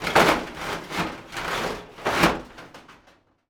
metal_sheet_impacts_18.wav